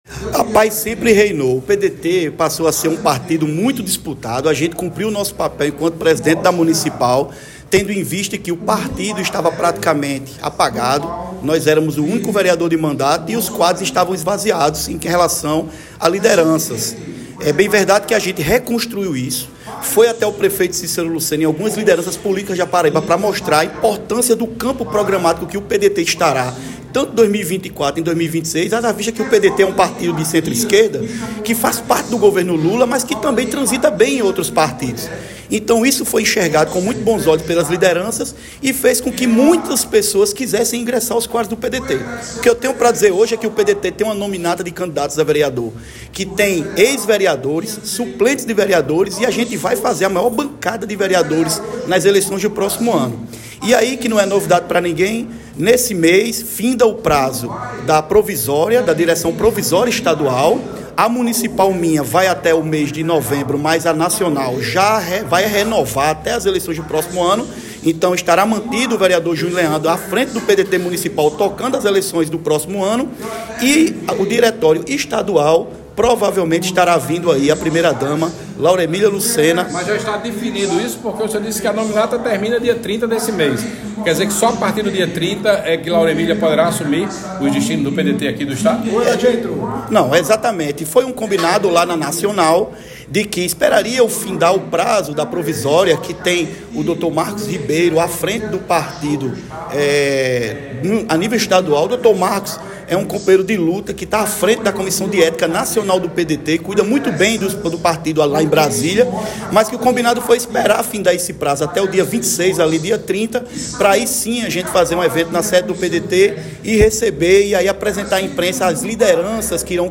Abaixo a fala do vereador Junio Leandro.